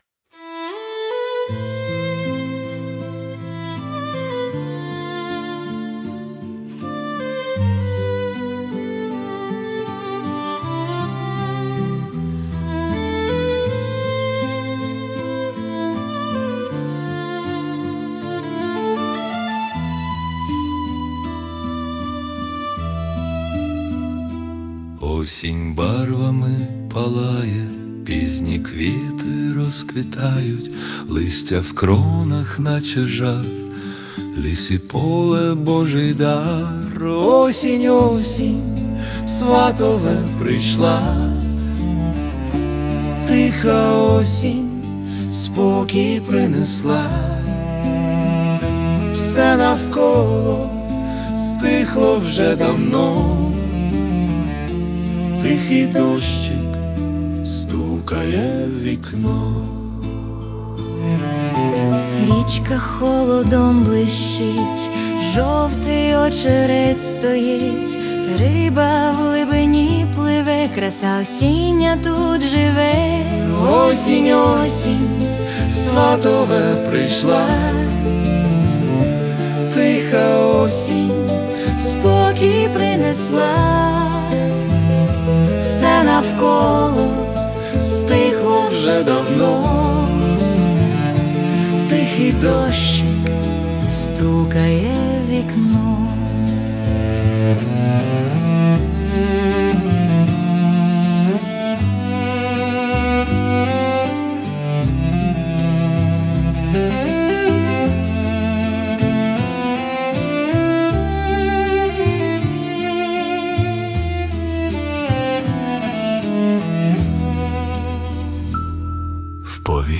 Autumn Ballad / Acoustic